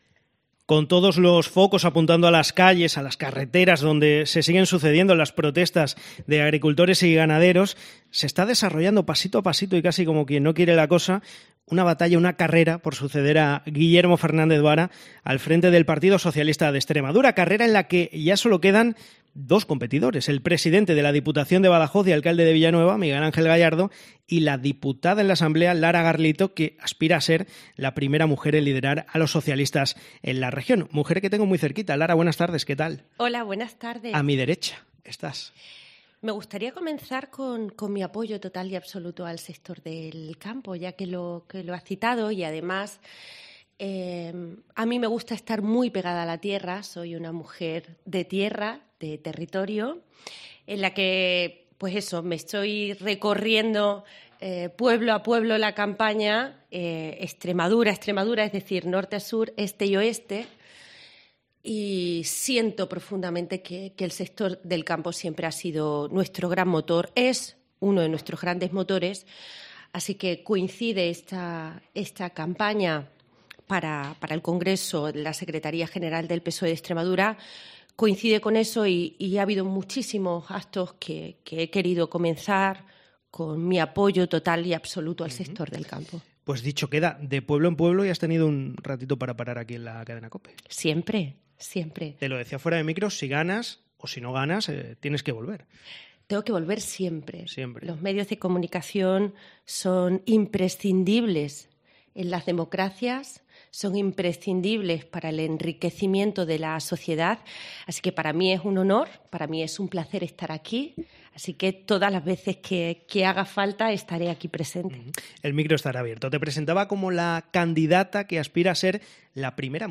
La candidata a suceder a Vara al frente del PSOE de Extremadura, que se ve como la mejor preparada, ha concedido su primera entrevista en radio.
La de COPE Extremadura es su primera entrevista en radio.